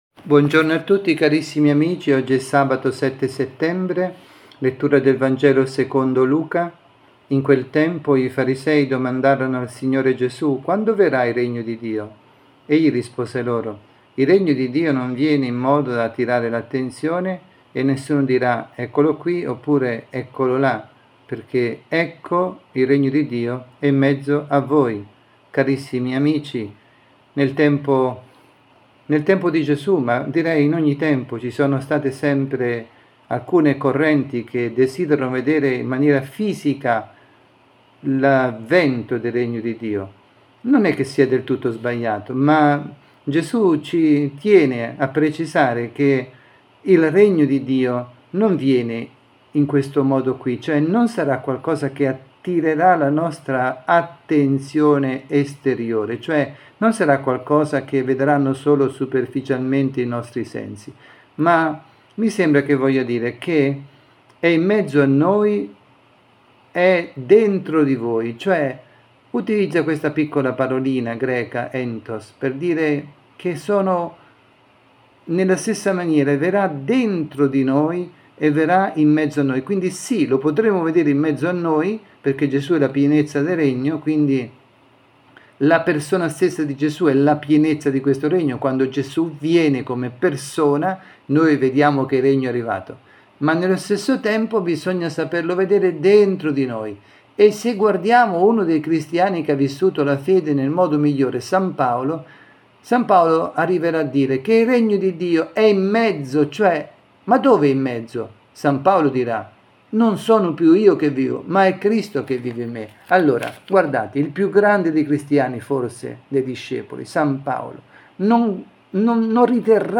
avvisi, Catechesi, Omelie